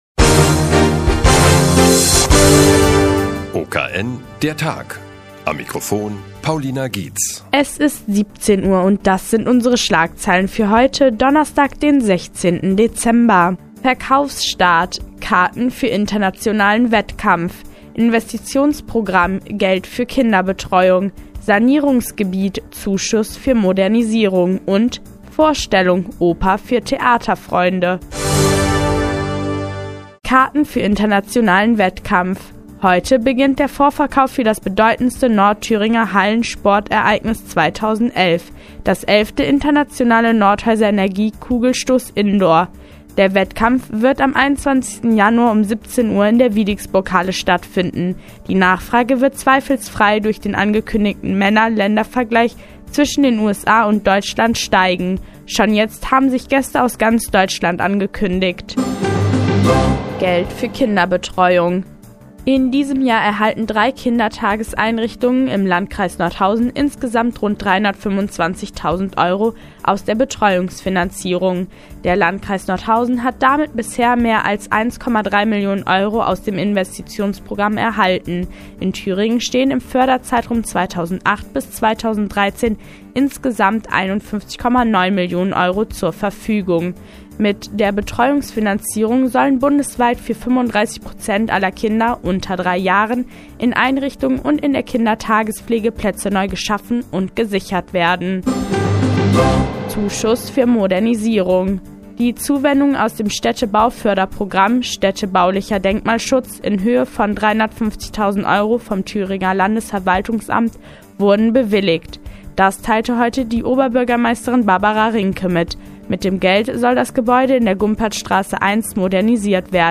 16.12.2010, 16:00 Uhr : Seit Jahren kooperieren die nnz und der Offene Kanal Nordhausen. Die tägliche Nachrichtensendung des OKN ist nun auch in der nnz zu hören.